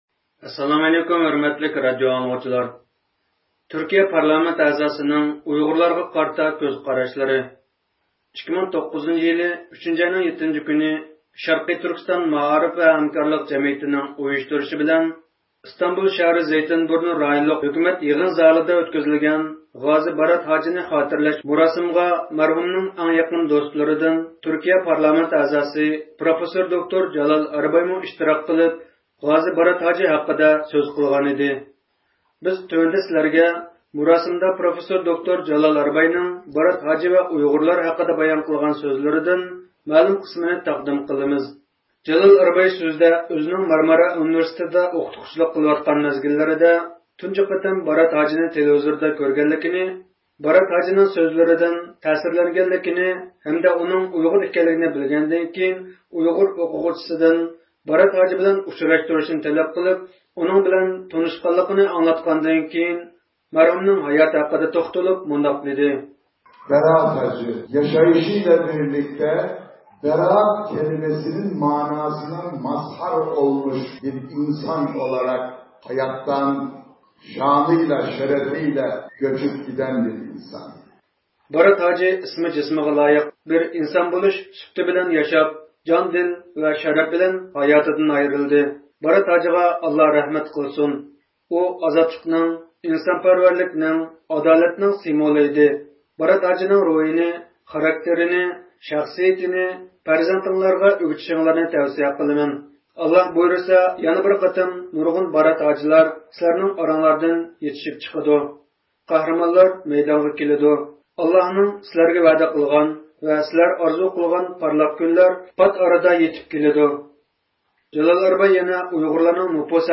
2009 - يىلى 3 – ئاينىڭ 7 - كۈنى شەرقىي تۈركىستان مائارىپ ۋە ھەمكارلىق جەمئىيىتىنىڭ ئۇيۇشتۇرۇشى بىلەن ئىستانبۇل شەھىرى زەيتىنبۇرنۇ رايونلۇق ھۆكۈمەت يىغىن زالىدا ئۆتكۈزۈلگەن «غازى بارات ھاجىنى خاتىرىلەش» مۇراسىمىغا مەرھۇمنىڭ ئەڭ يېقىن دوستلىرىدىن تۈركىيە پارلامېنت ئەزاسى پروفېسسور دوكتۇر جالال ئەربايمۇ ئىشتىراك قىلىپ غازى بارات ھاجى ھەققىدە سۆز قىلغان ئىدى.
پارلامېنت ئەزاسى جالال ئەرباي سۆزىنى ئاخىرلاشتۇرغاندا مۇراسىم ئىشتىراكچىلىرىدىن بەزى ئۇيغۇرلارنىڭ سورىغان سوئاللىرىغا جاۋاپ بەردى.